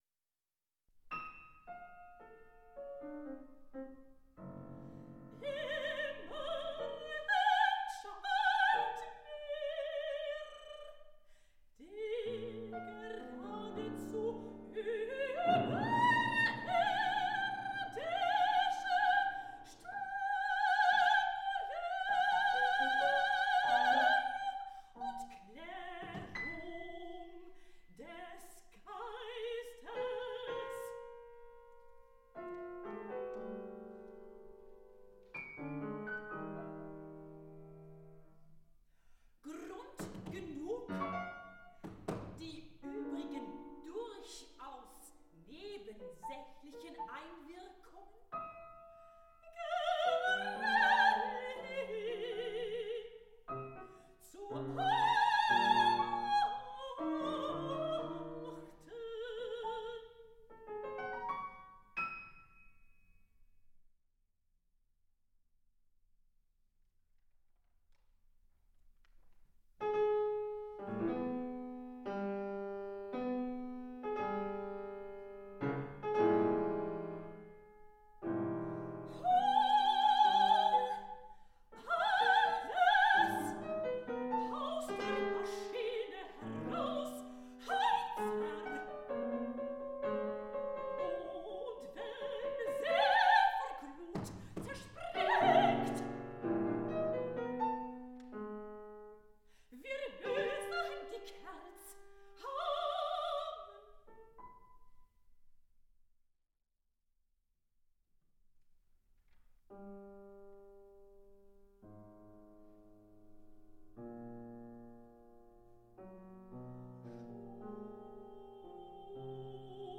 in drei bedeutungslosen Liedern
für Sopran und Klavier
02.1992 / UA 14.02.1992, Lübeck, Musikhochschule